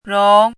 chinese-voice - 汉字语音库
rong2.mp3